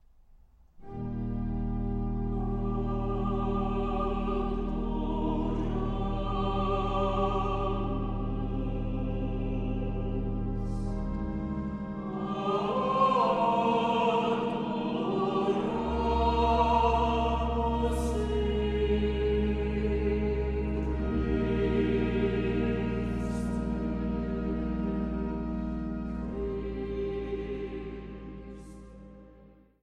Orgel
eine Nelson-Orgel von 1904